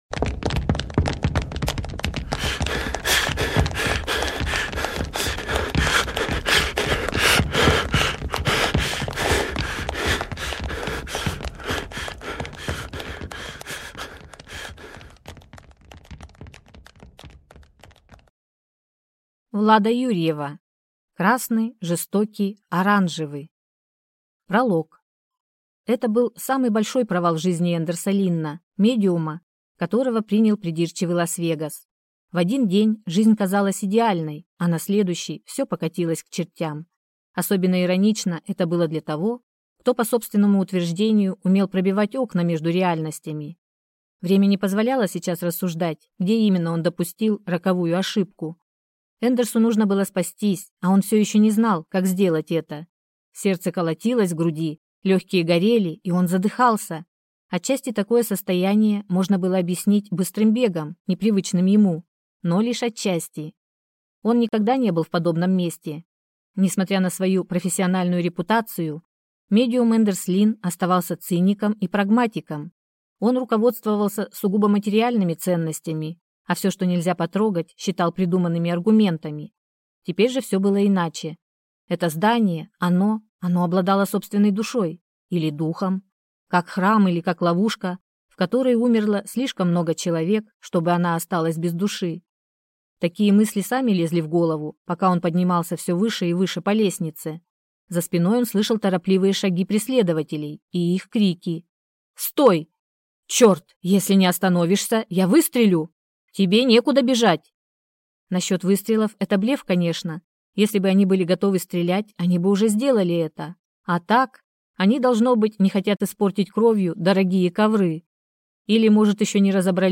Аудиокнига Красный, жестокий, оранжевый | Библиотека аудиокниг